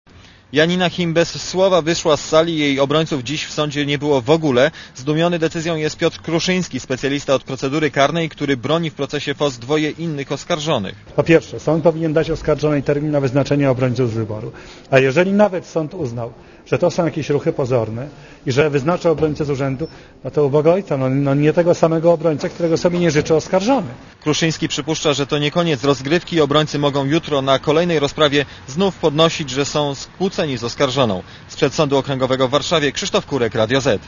Relacja reportera Radia ZET